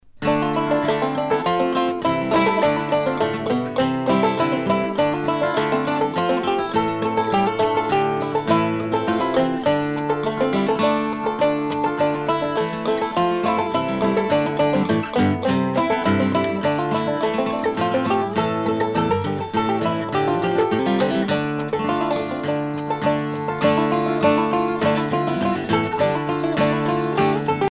Bartok : Duo pour violon